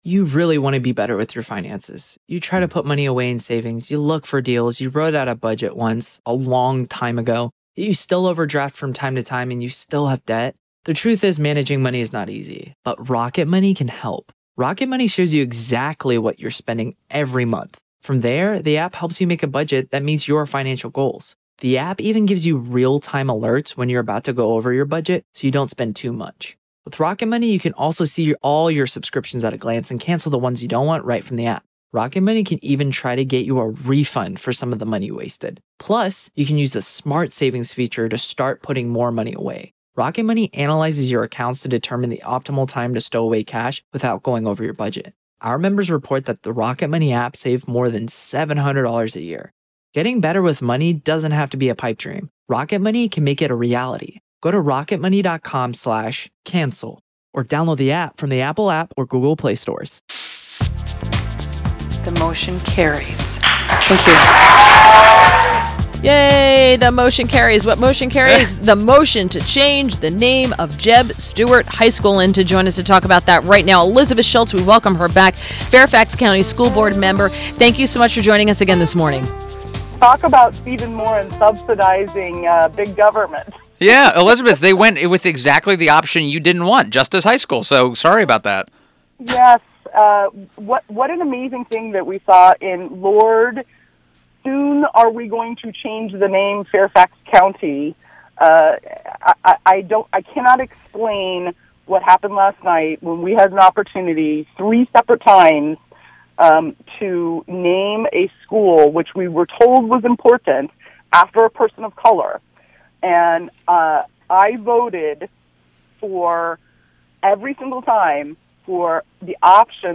INTERVIEW - Elizabeth Schultz - Fairfax County School Board member